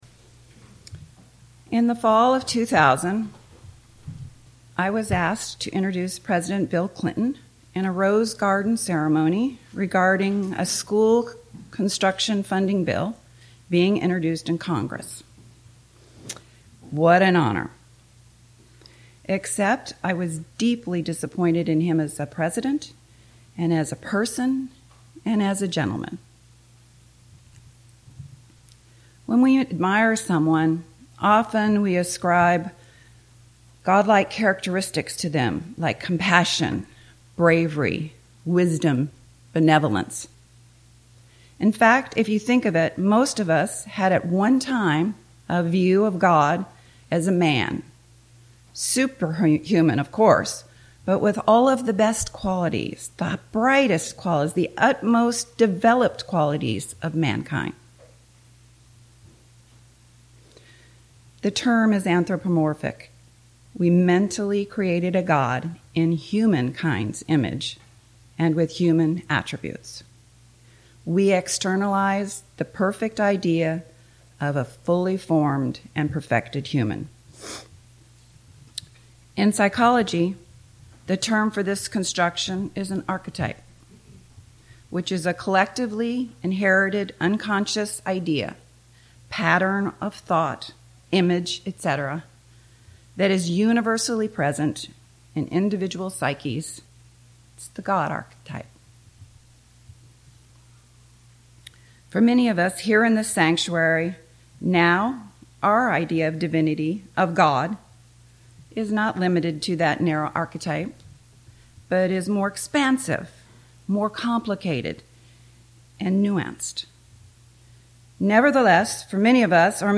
This sermon explores the dynamic nature of spirituality, framing it as an active struggle to balance personal conscience with social and biological influences.